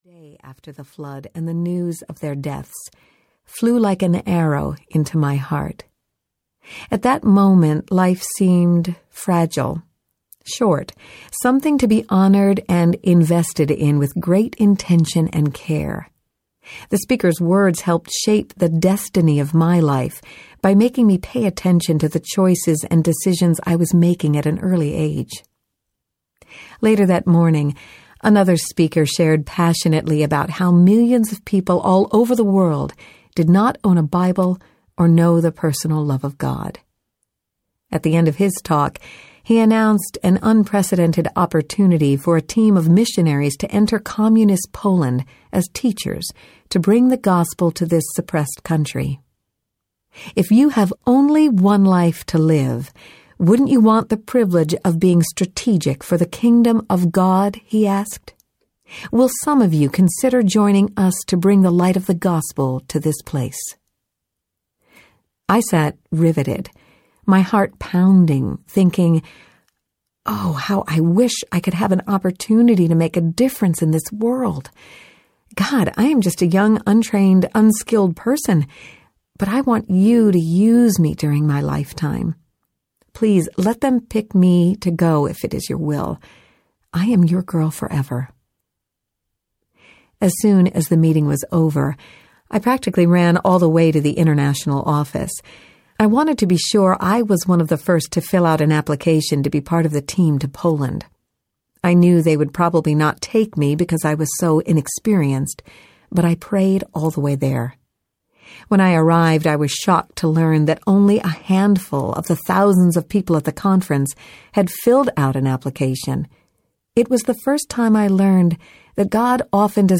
Own Your Life Audiobook
Narrator
8.58 – Unabridged